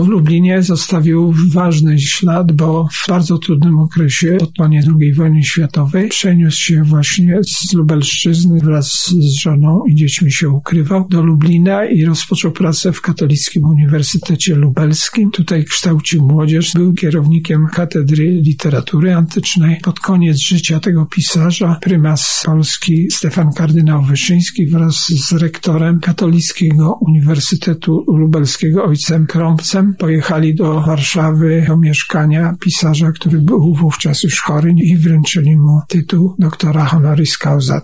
polonista i filolog klasyczny